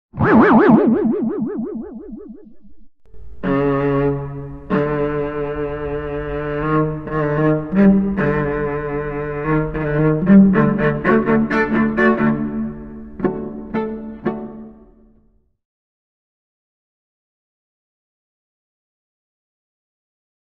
• Качество: 128, Stereo
без слов
инструментальные
виолончель
загадочные
цикличные